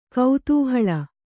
♪ kautūhaḷa